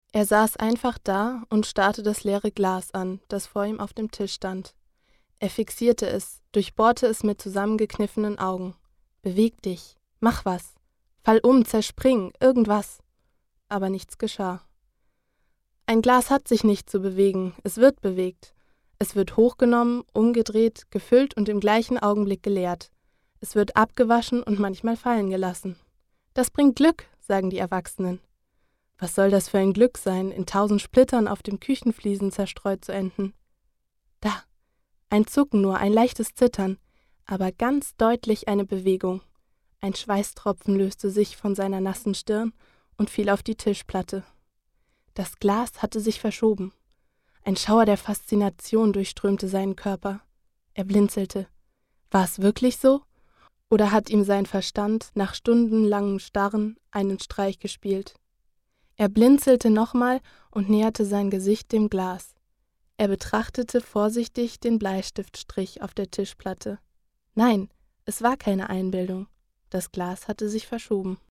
Sprecherin deutsch
Kein Dialekt
Sprechprobe: Werbung (Muttersprache):
german female voice over artist